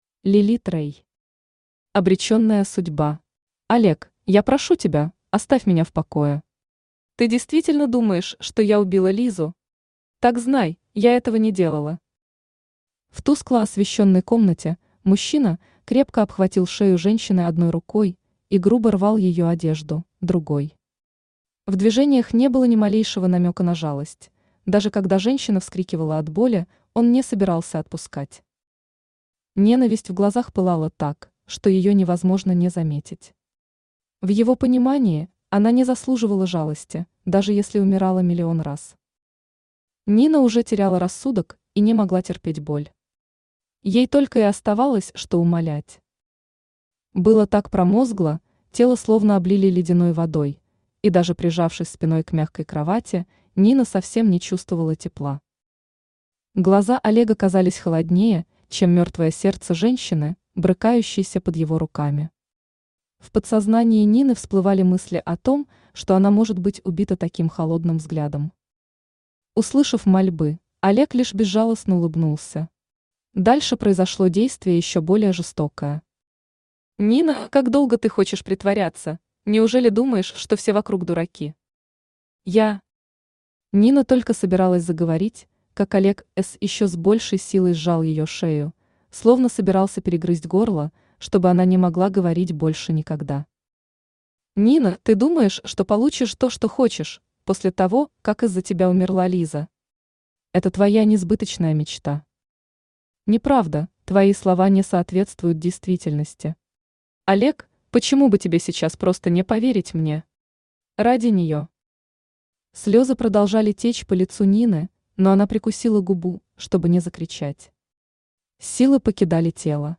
Аудиокнига Обречённая судьба | Библиотека аудиокниг
Aудиокнига Обречённая судьба Автор Лилит Рэй Читает аудиокнигу Авточтец ЛитРес.